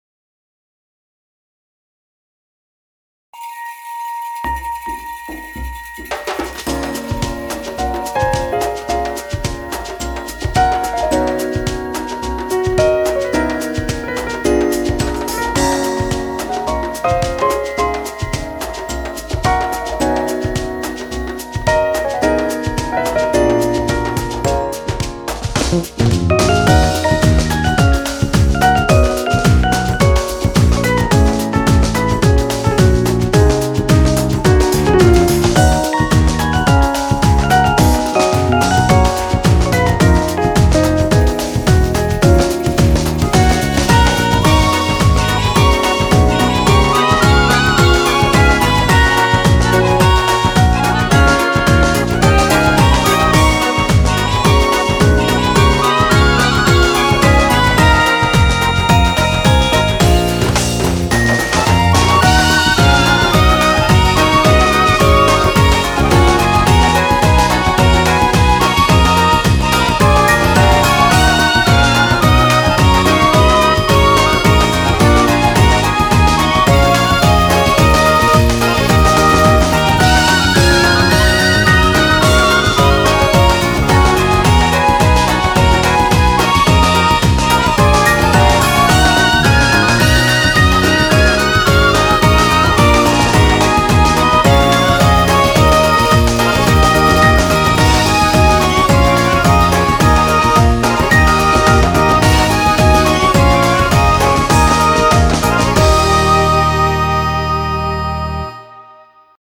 BPM88-108
Audio QualityPerfect (High Quality)